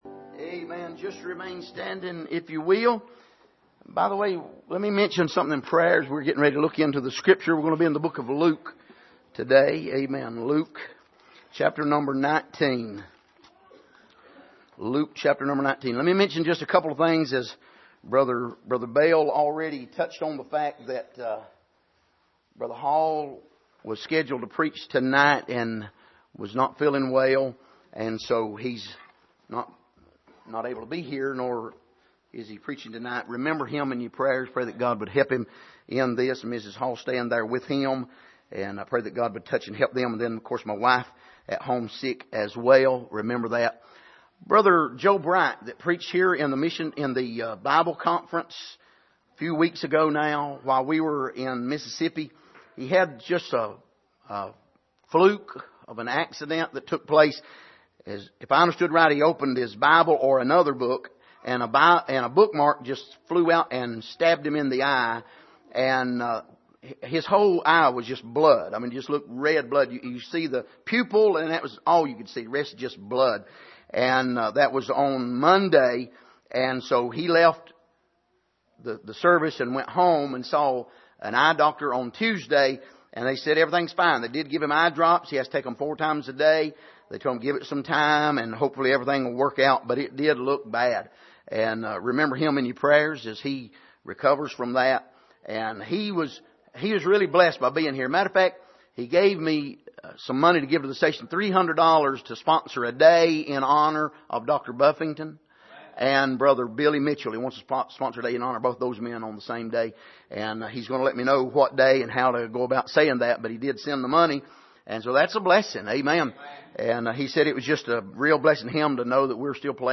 Passage: Luke 23:38-43 Service: Sunday Evening